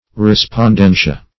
Respondentia \Re`spon*den"ti*a\ (r?`sp?n*d?n"sh?*?), n. [NL. See